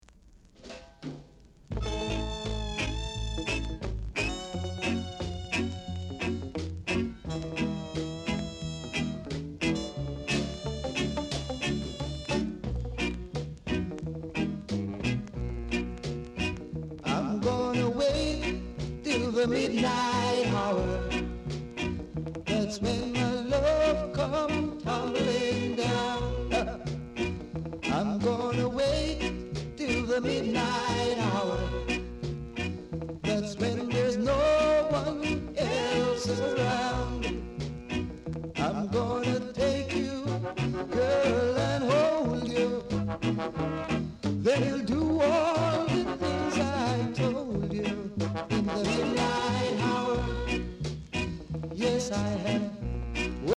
R.Steady Vocal Group